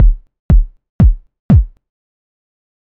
BD01 Drum
Simulates a bass drum. Based on a Csound drum.